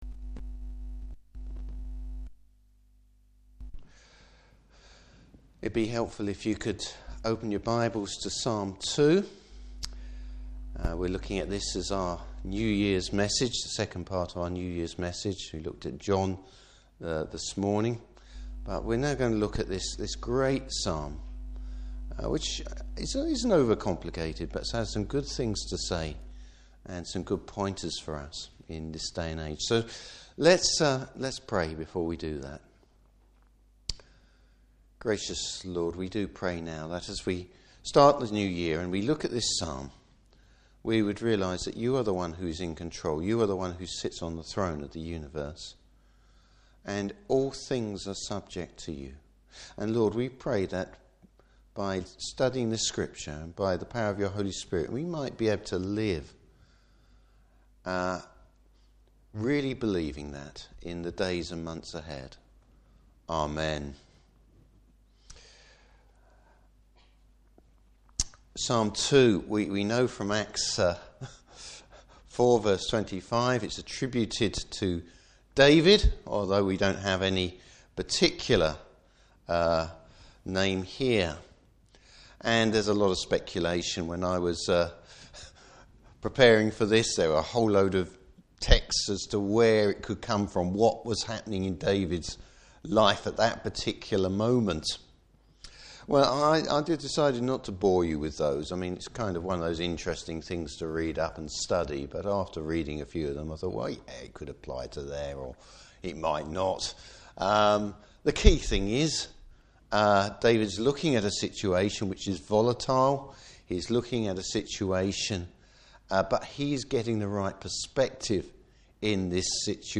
New Year’s Sermon: Getting the Right Perspective.
Service Type: Evening Service The reality of the world and history.